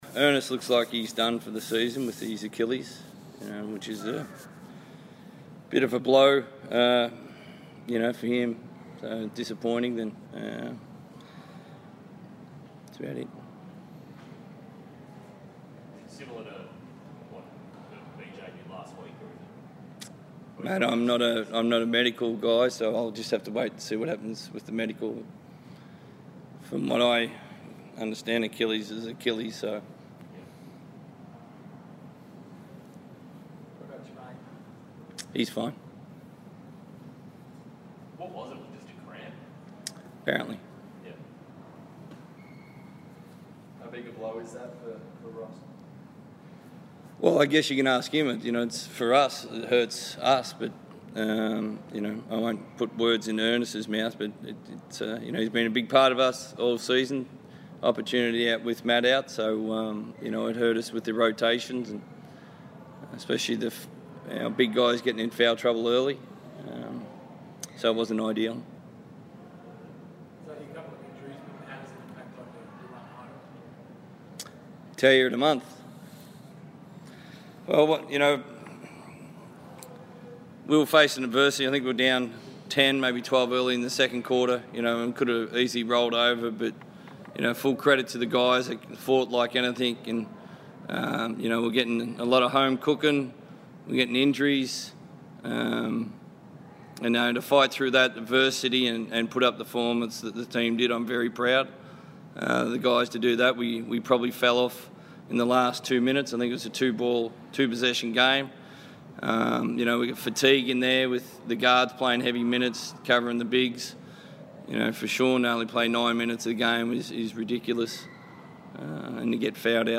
speak to the media following the Perth Wildcats defeat versus the Cairns Taipans.